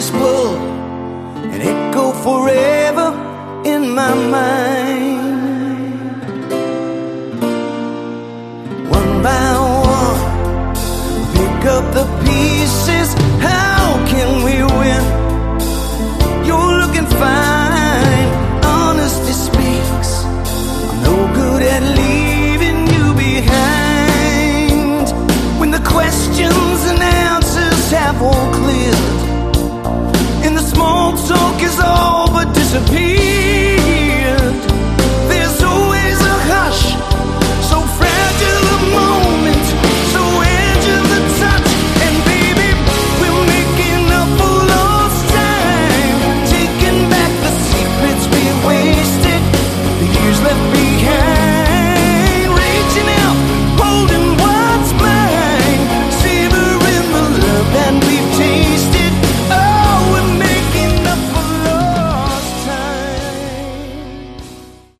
Category: AOR / Melodic Hard Rock